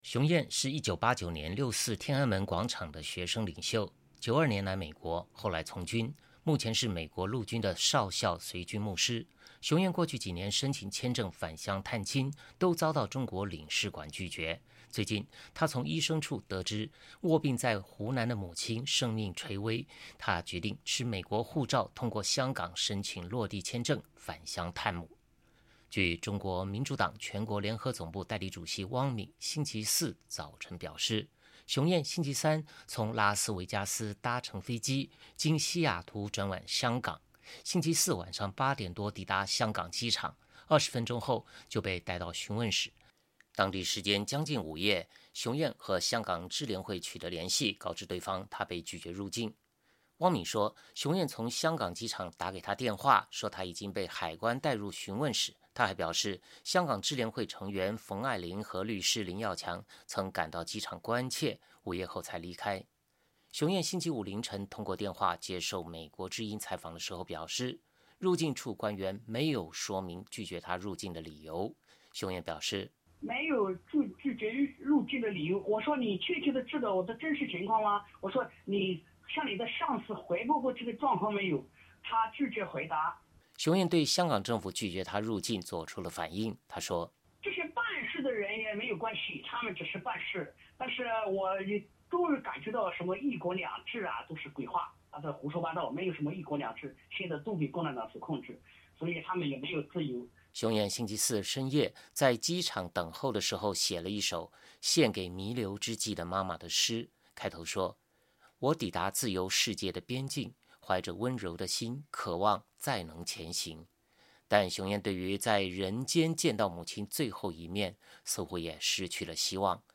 熊焱星期五凌晨通过电话接受美国之音采访时表示，入境处官员没有说明拒绝他入境的理由，熊焱表示：“没有拒绝入境的理由，我说你确切知道我真实的情况吗？我说你向你的上司汇报过这个状况没有，他拒绝回答。”